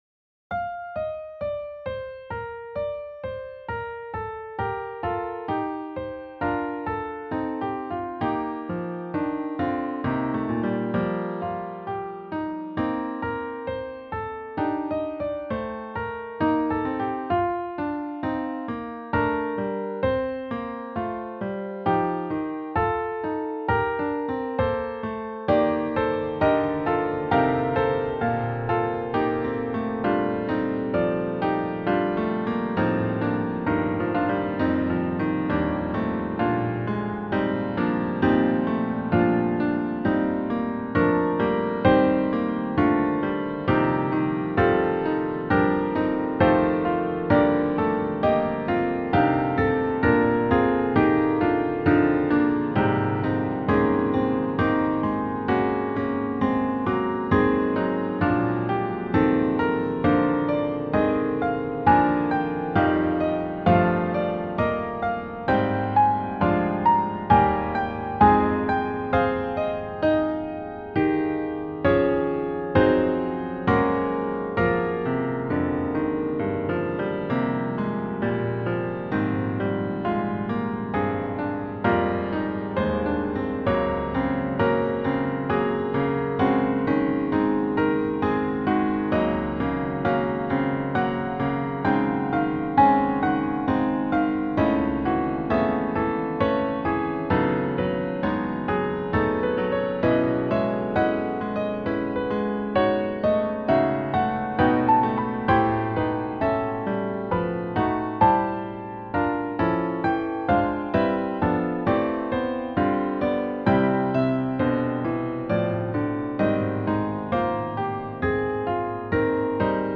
for SATB and organ
The long phrases express the grandeur of the text.
Music Type : Choral
Voicing : SATB Accompaniment : Organ